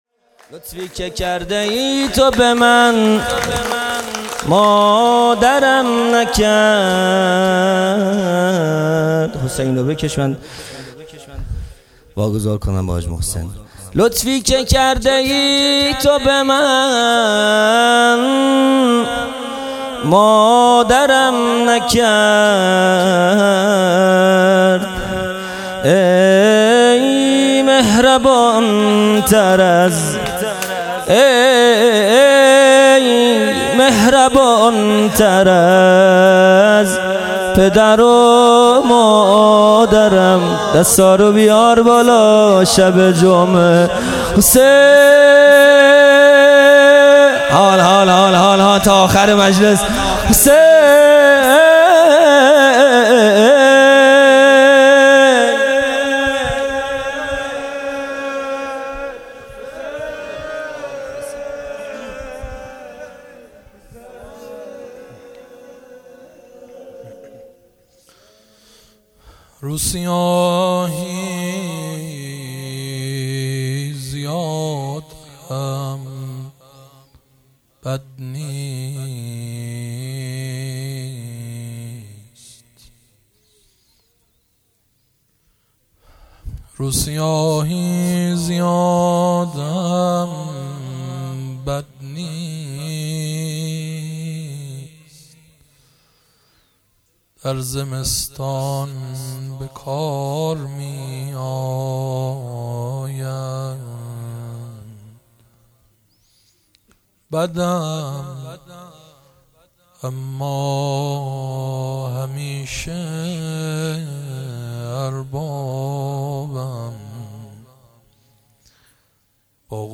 خیمه گاه - هیئت بچه های فاطمه (س) - مدح|پنج شنبه ۱۶ بهمن ۹۹